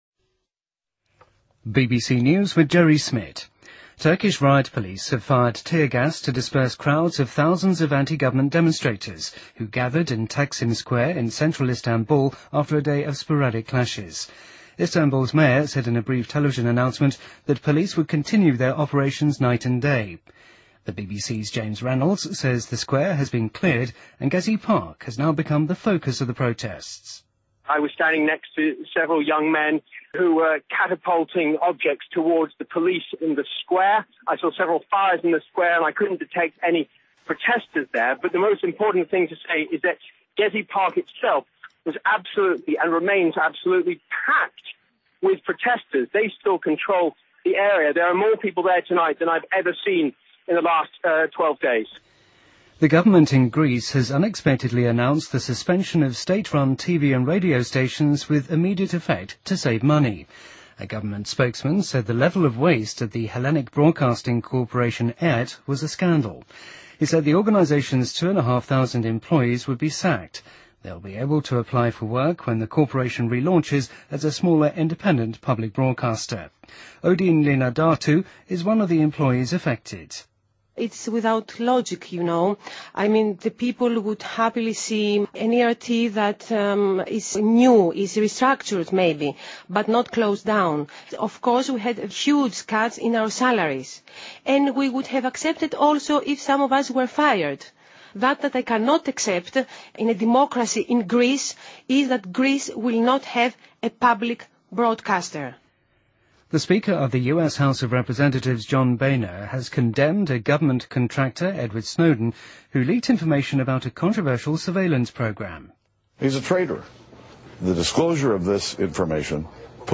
BBC news,2013-06-12